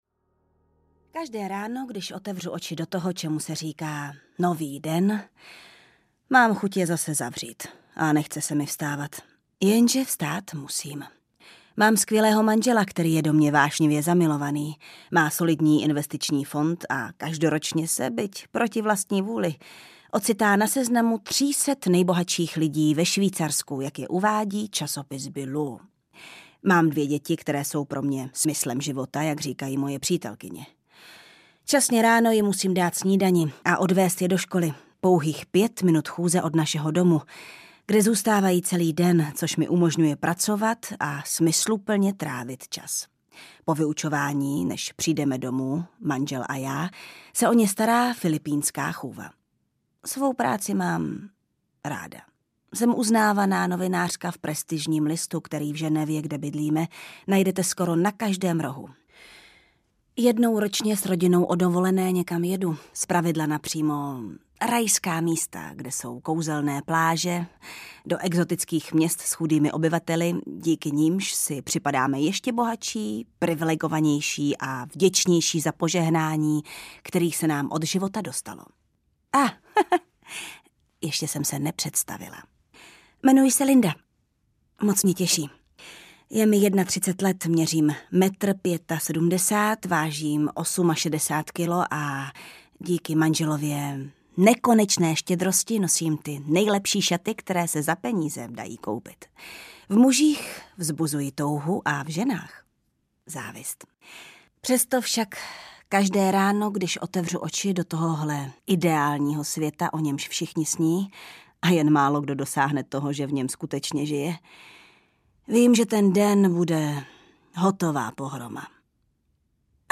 Nevěra audiokniha
Ukázka z knihy
• InterpretJana Stryková